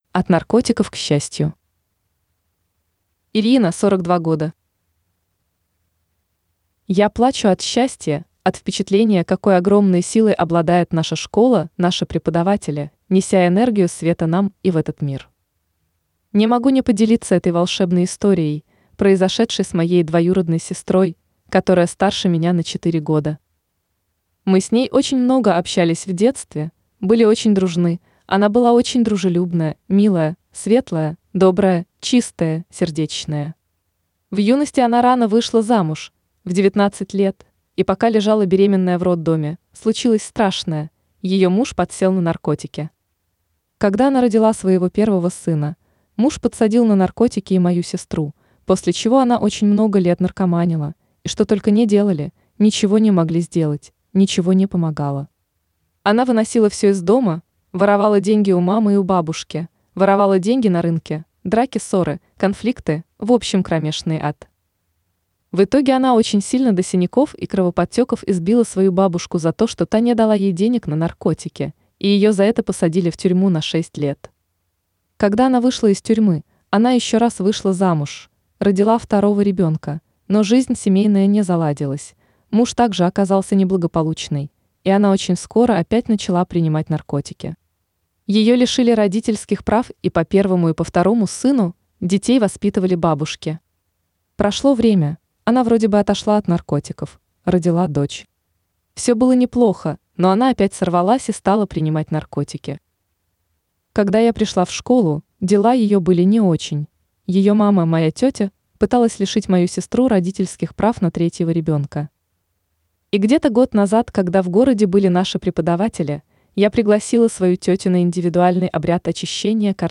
Жанр: Аудио книга.